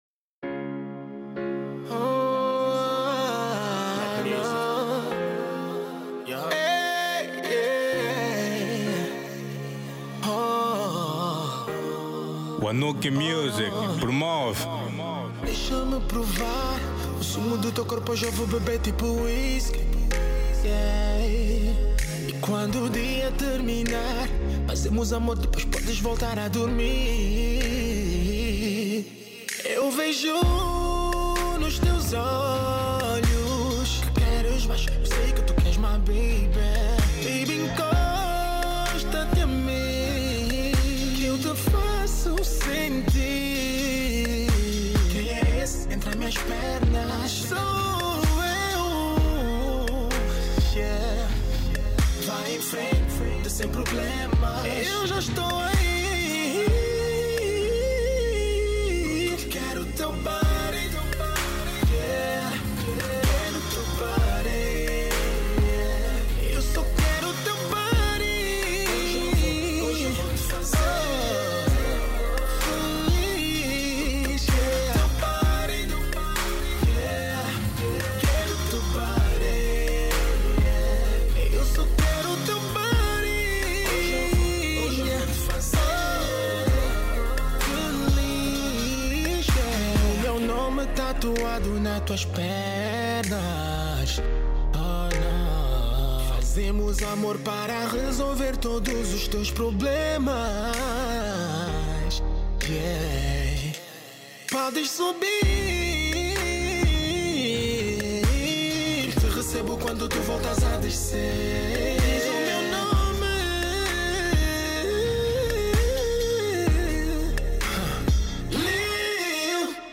Genero: Kizomba